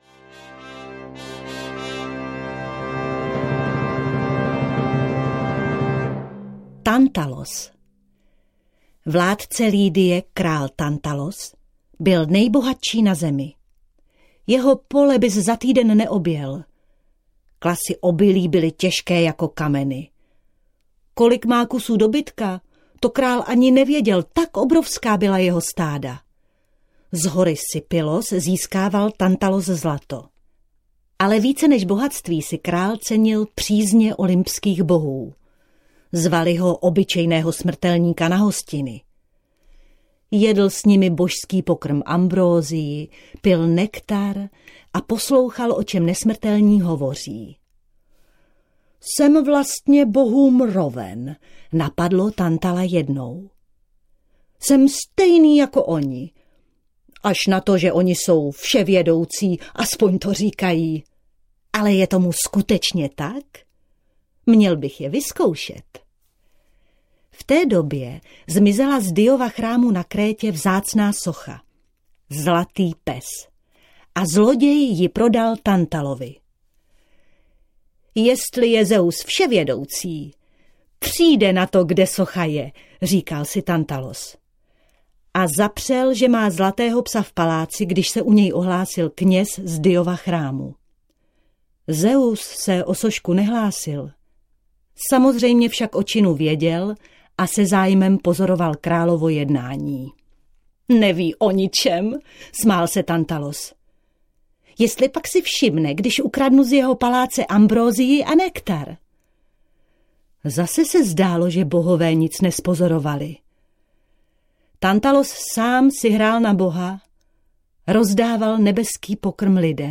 Audio kniha
Ukázka z knihy